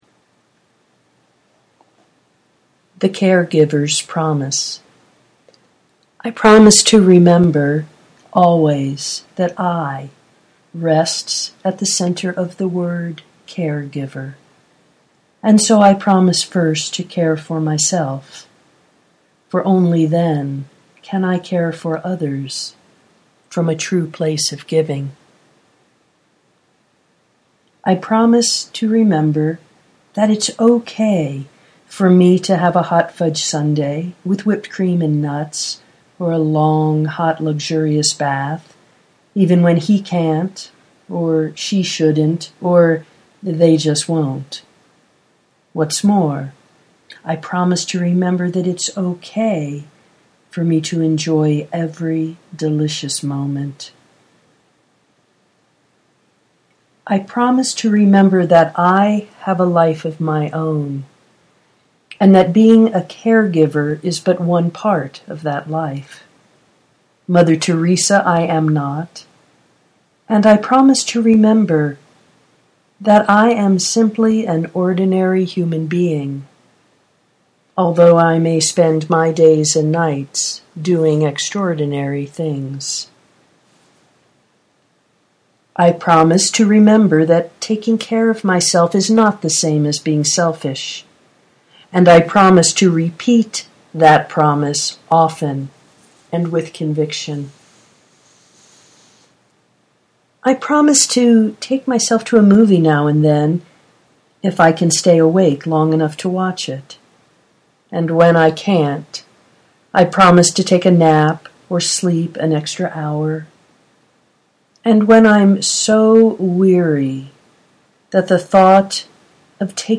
(regular voice, no Echoes of the Deep Void) for all of you out there
This reading is in honor of caregivers everywhere.